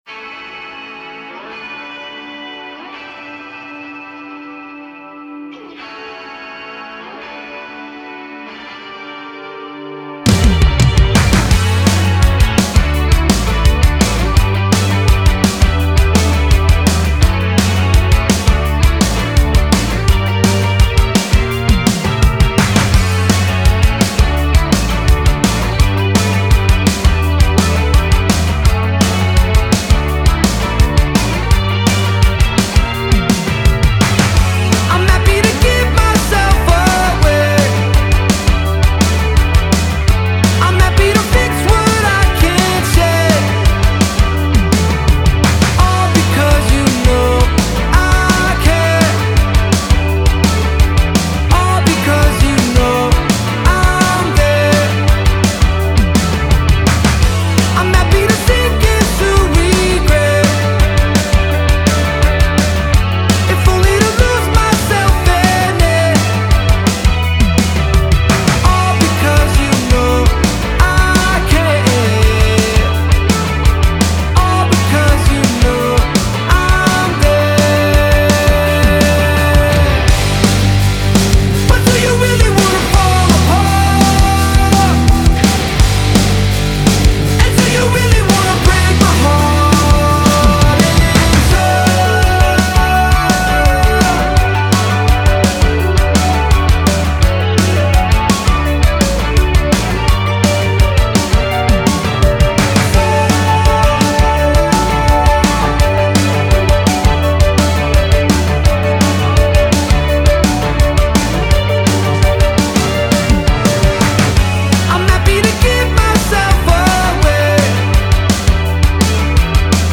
Трек размещён в разделе Зарубежная музыка / Рок.